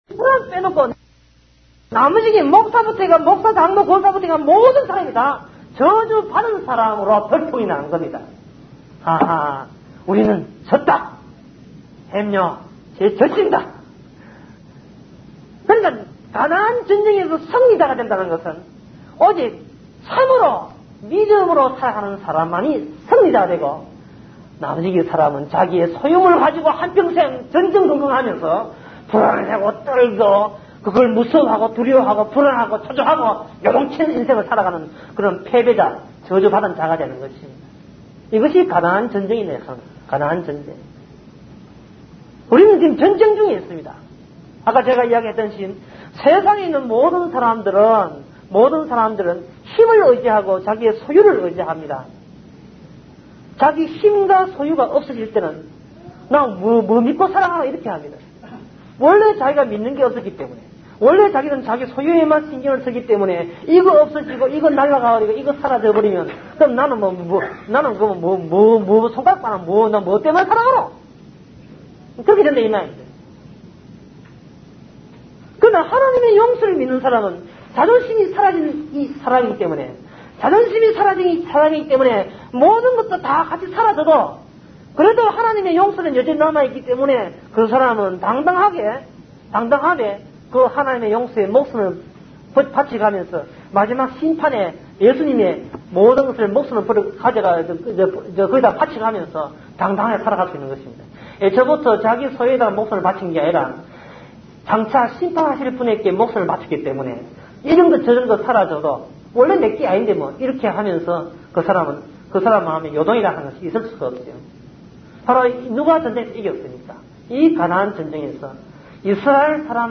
사사기 강해 (2007)